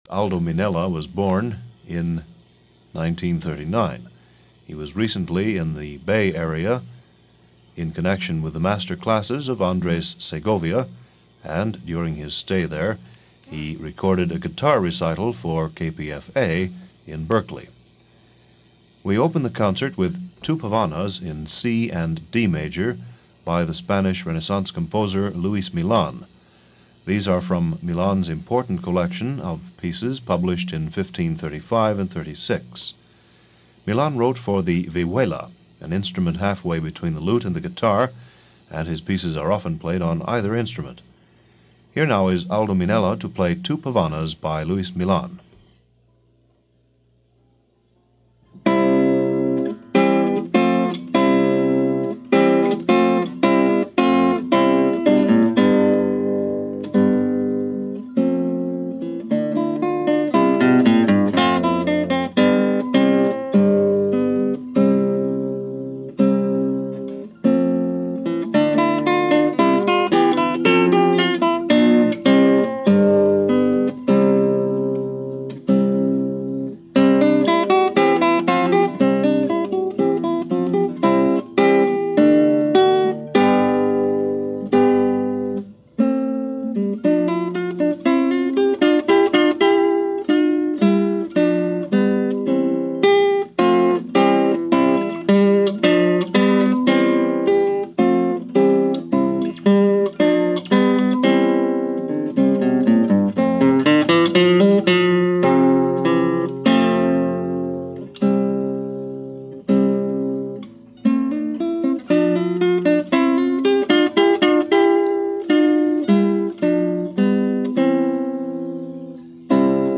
A Guitar recital this week